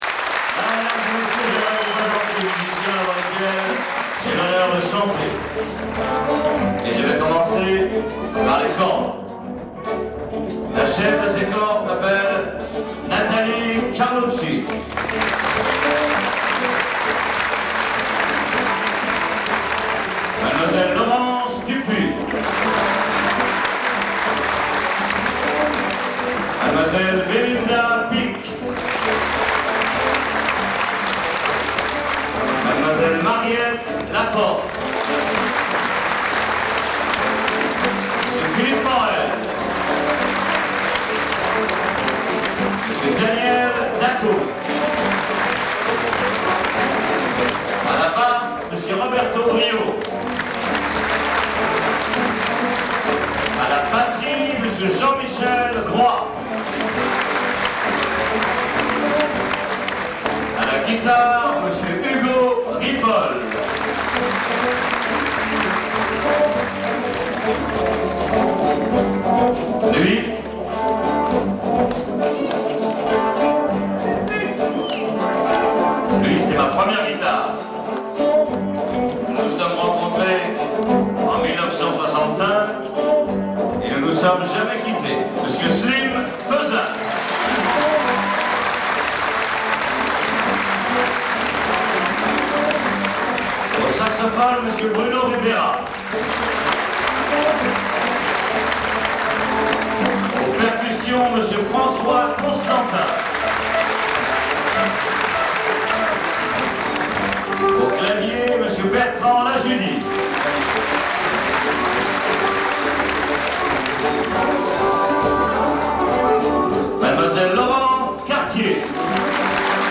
Bootlegs (enregistrements en salle)
Olympia (24 mai 1995)